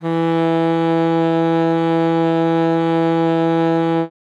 42c-sax02-e3.wav